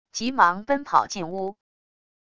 急忙奔跑进屋wav音频